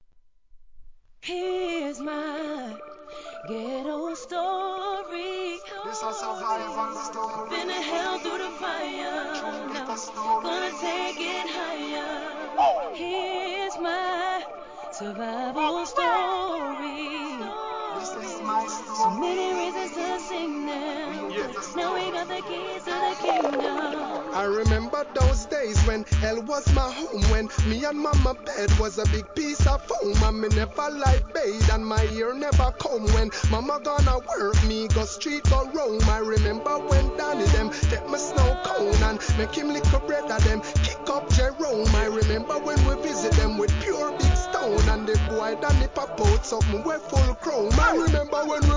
HIP HOP/R&B
サビも彼女が歌っておりますがGOO~D!!